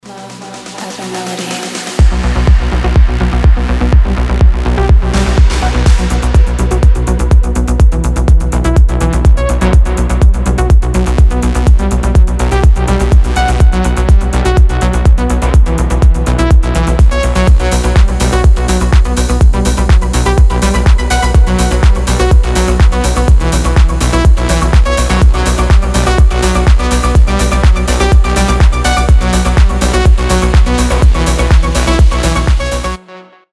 Стиль: progressive house